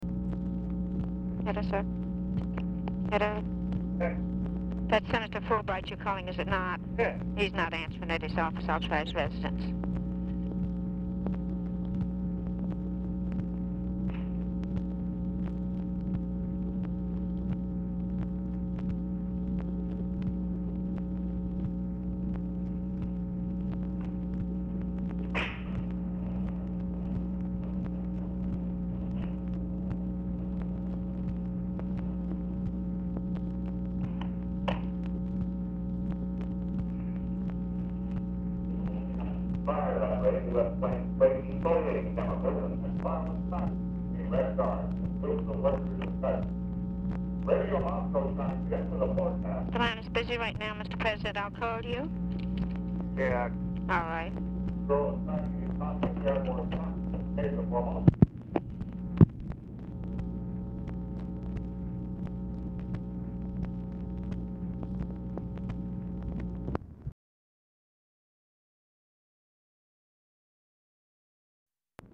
TV OR RADIO AUDIBLE IN BACKGROUND AT TIMES
Format Dictation belt
OFFICE NOISE
Specific Item Type Telephone conversation